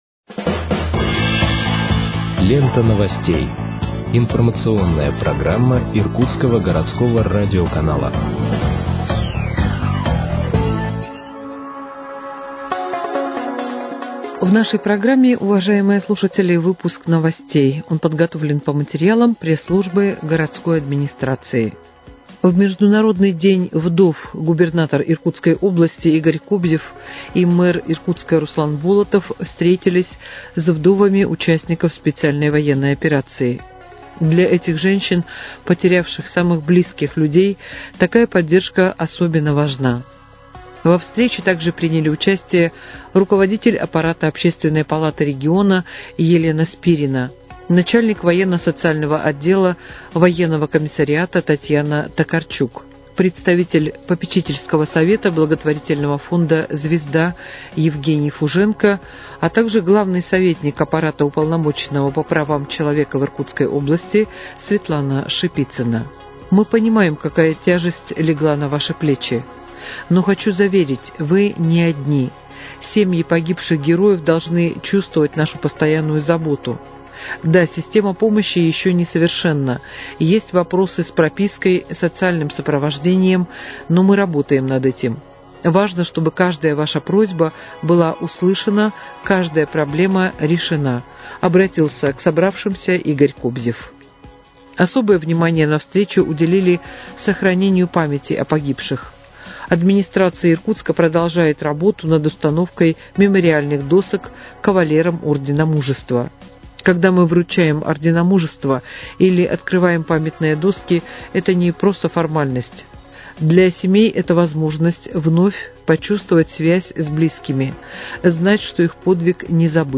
Выпуск новостей в подкастах газеты «Иркутск» от 25.06.2025 № 1